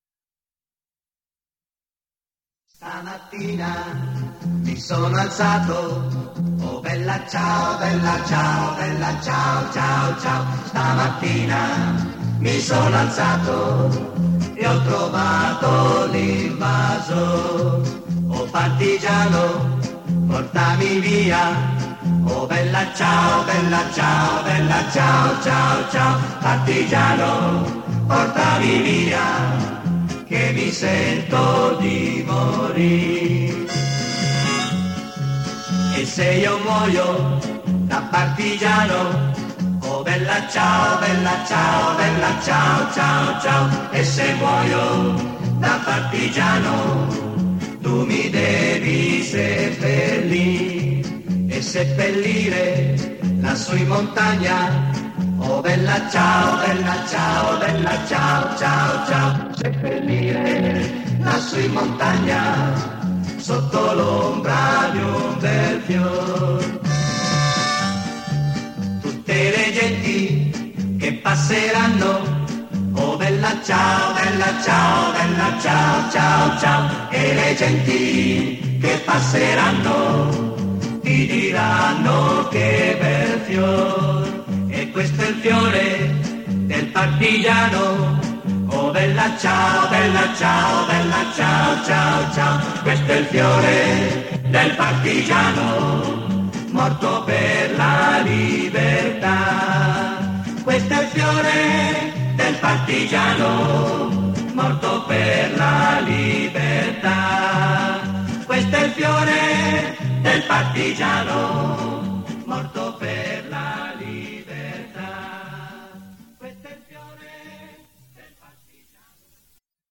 （外文演唱）
优质原音带